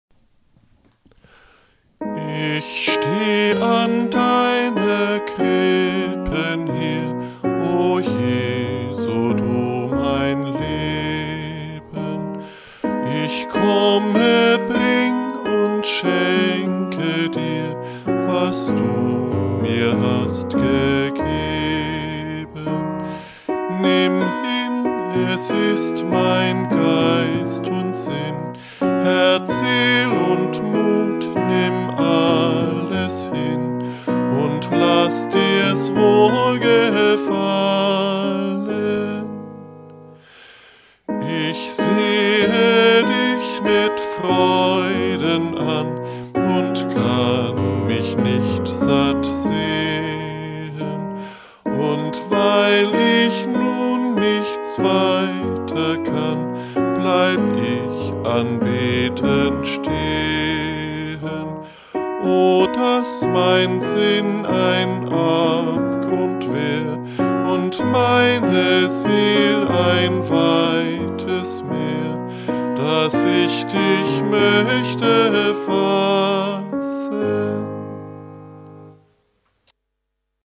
Vielleicht findet Ihr einen ruhigen Moment, um dieses alte Weihnachtslied von Paul Gerhard aus dem Jahr 1653 anzuhören.